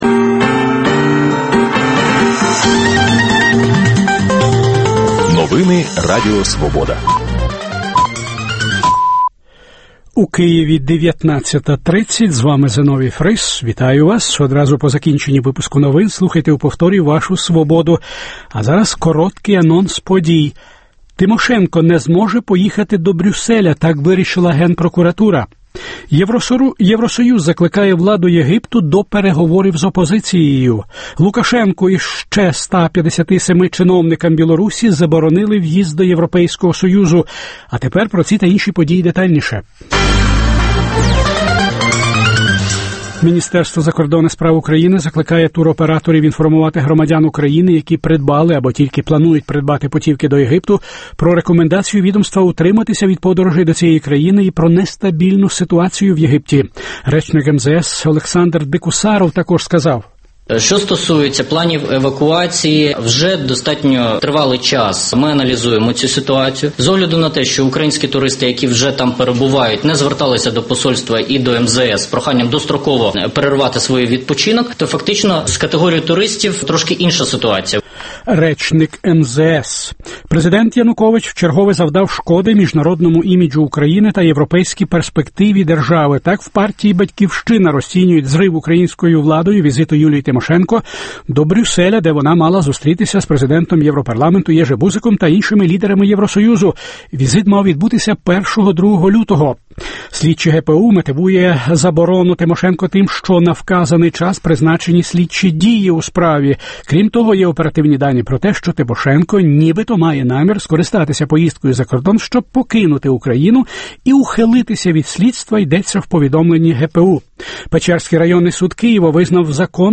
Дискусія про головну подію дня.